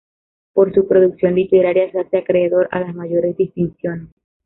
pro‧duc‧ción
/pɾoduɡˈθjon/